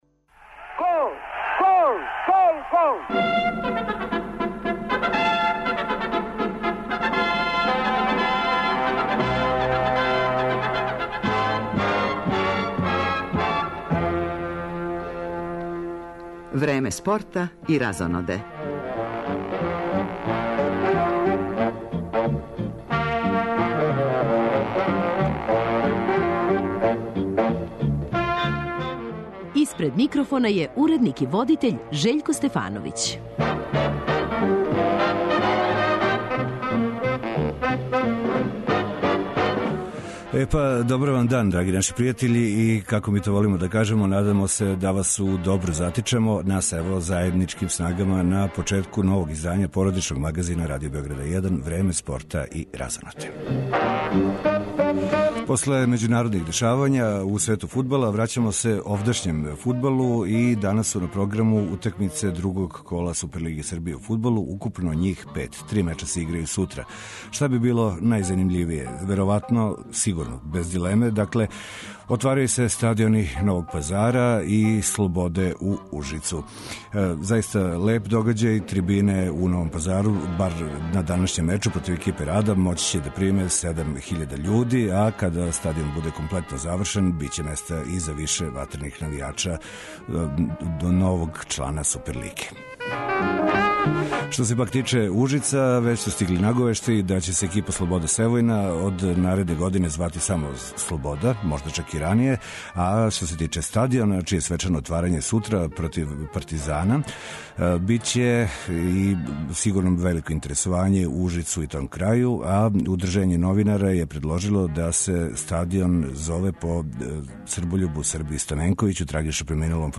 Током емисије, наши репортери јављаће се с неколико стадиона на којима се играју утакмице 2. кола Супер лиге Србије у фудбалу. Пратимо и кретање резултата у првенствима Немачке и Енглеске.